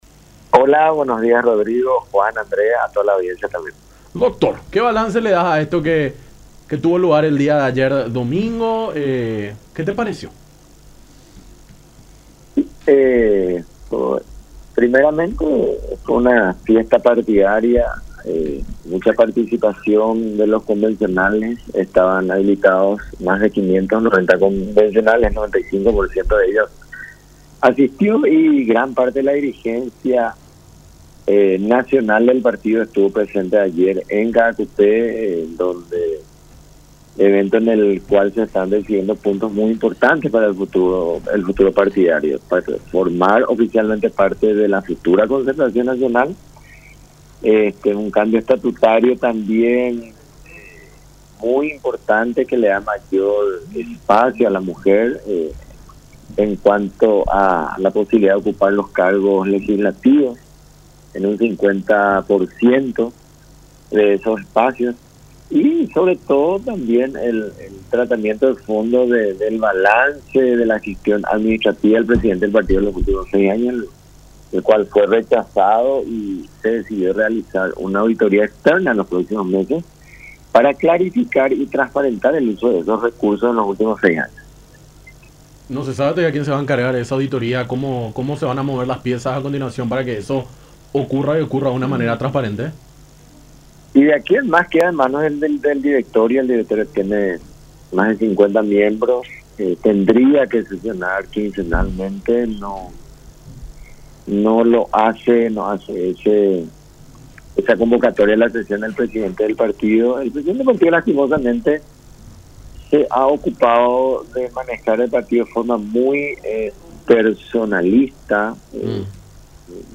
en charla con Nuestra Mañana a través de La Unión.